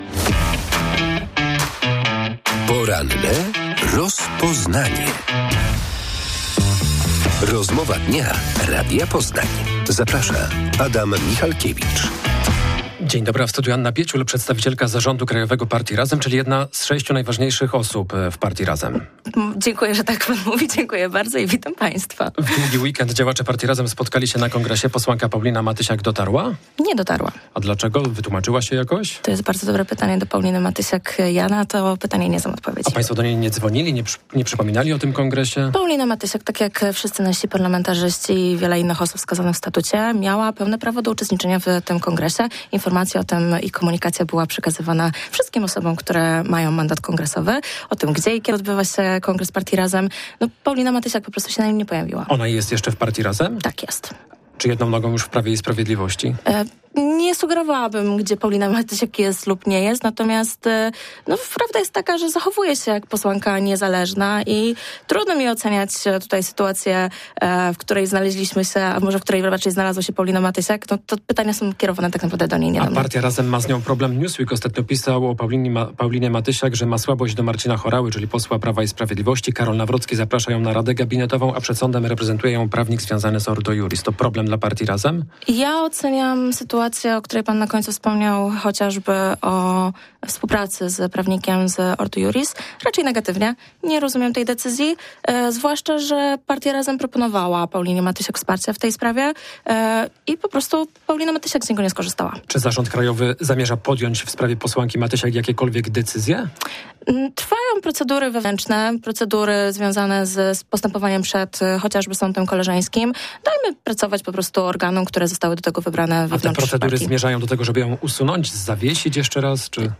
Partia Razem chce nowego podatku, który działacze nazywają antyspekulacyjnym. Miałby go płacić każdy, kto ma co najmniej trzy mieszkania. W porannej rozmowie Radia Poznań